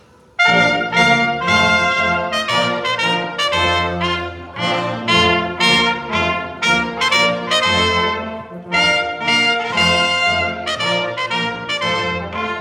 Besuch der Blechbläser des Musikschulkreises Lüdinghausen am 12. März
Denn wir hatten Besuch von einem Blechblasquintett des Musikschulkreises Lüdinghausen.
Fünf Musizierende stellten uns die verschiedenen Blechblasinstrumente vor und begeisterten die Schülerinnen und Schüler sowohl mit dem Knochenlied als auch mit Tanzliedern aus verschiedenen Jahrhunderten.